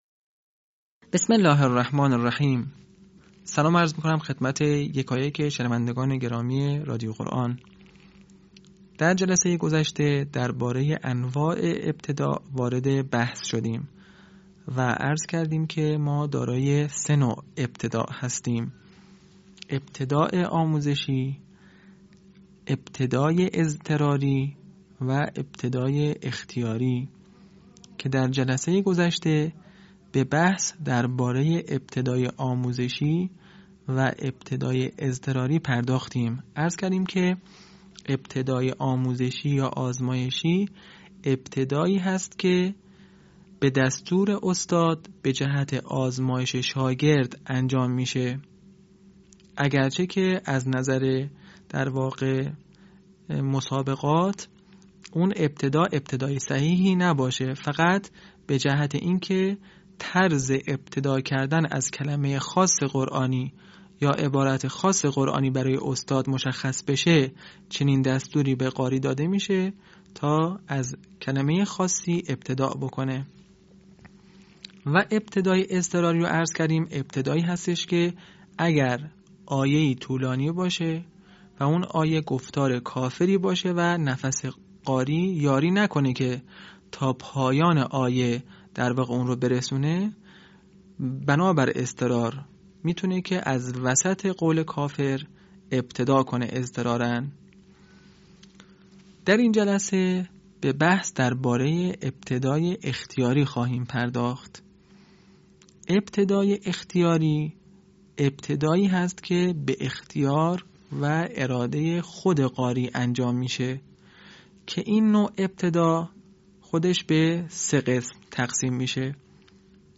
به همین منظور مجموعه آموزشی شنیداری (صوتی) قرآنی را گردآوری و برای علاقه‌مندان بازنشر می‌کند.
آموزش قرآن